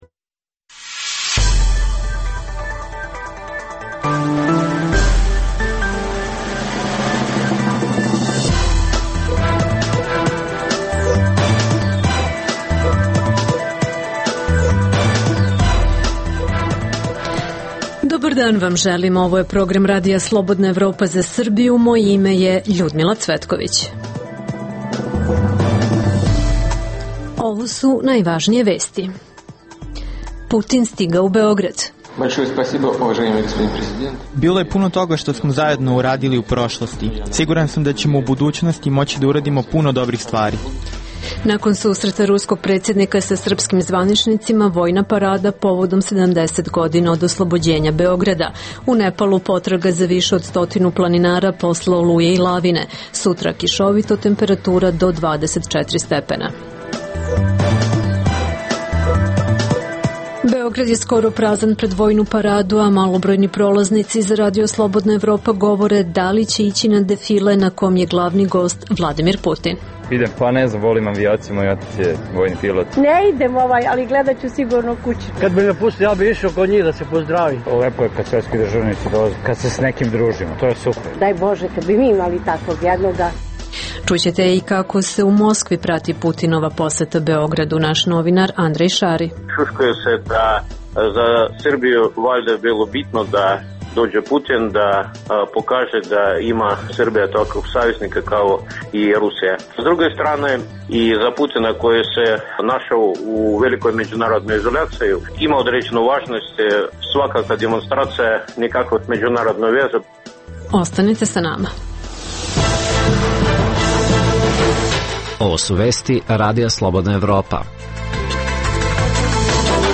U emisiji poslušajte: - Rusija ne trguje svojim prijateljstvom, poručio Putin u Beogradu. - Prenosimo atmosferu ispred Palate Srbija gde se očekuje početak vojne parade. - Čućete Beograđane, ali i one iz regiona koji su krenuli na paradu.